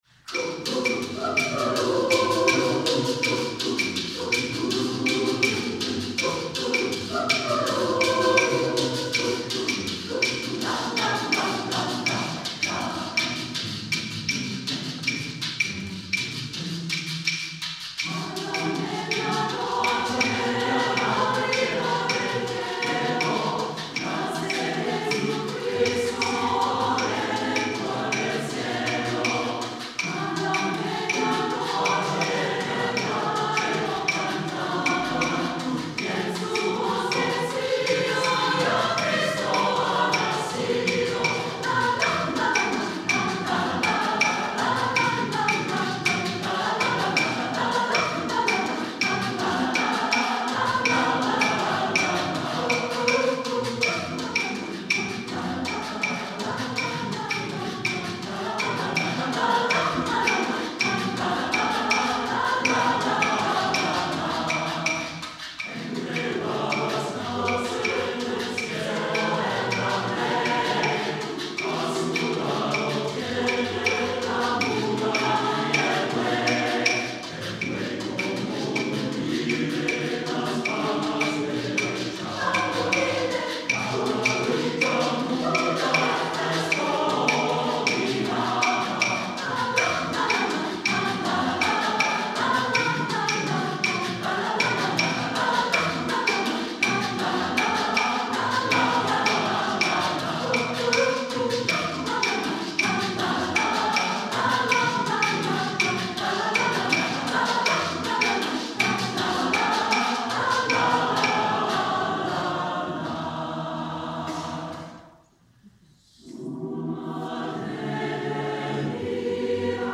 SATB a cappella + opt. Percussion. 2’40”